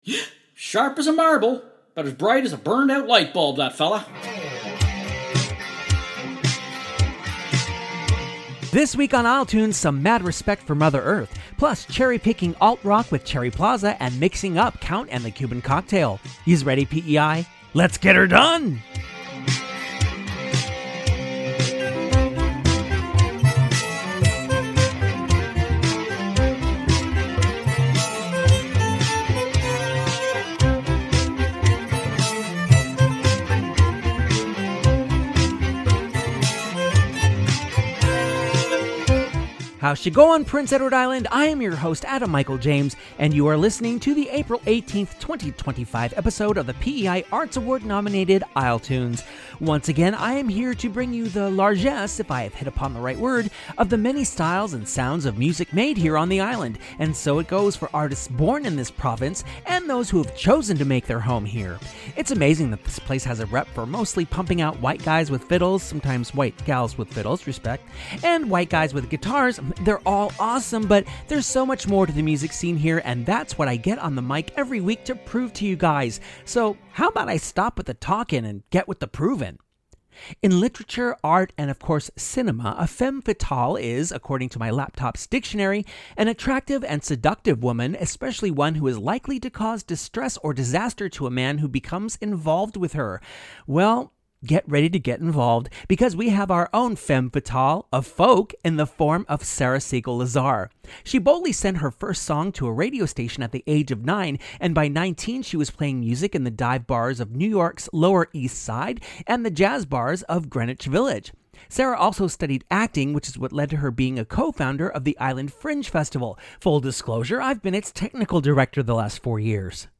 Look back at 2024 through 6 interviews conducted with PEI artists who sat for the isletunes microphones and hear the songs that went with 'em. isletunes is all genres.